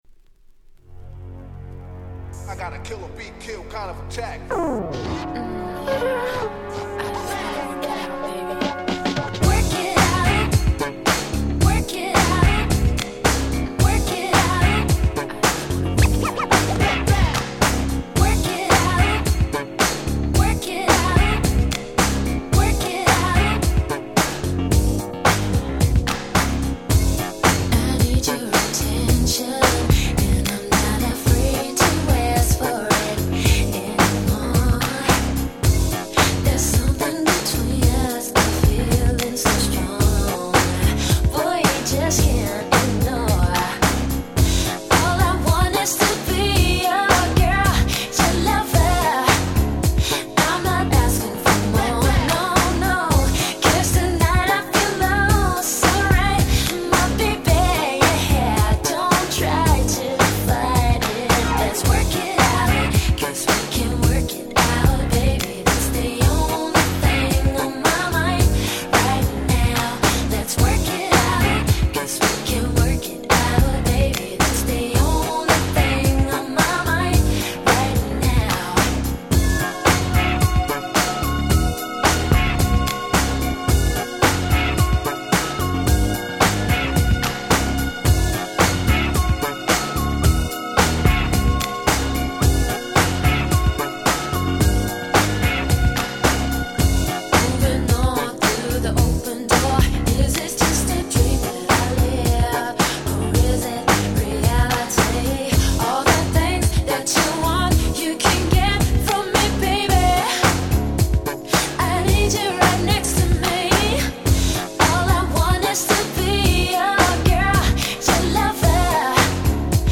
97' Nice EU R&B !!